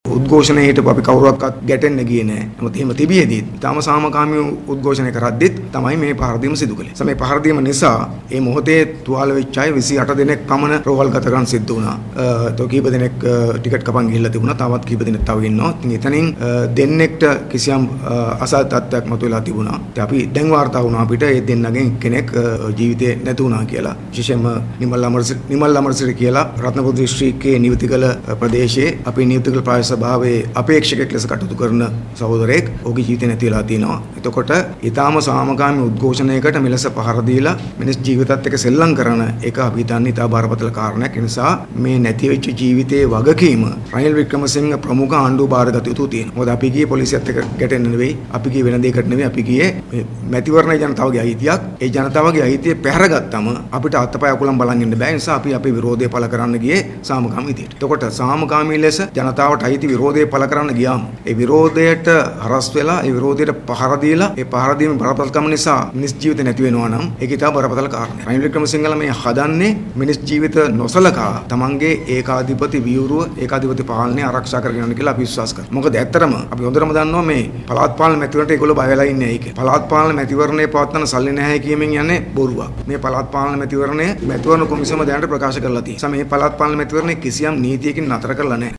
මේ සම්බන්ධයෙන් අද පැවති මාධ්‍ය හමුවකදී ජනතා විමුක්ති පෙරමුණේ නායක ටිල්වින් සිල්වා මහතා සදහන් කළේ මෙම මරණය සම්බන්ධ වගකීම ආණ්ඩුව විසින් බාර ගත යුතු බවයි.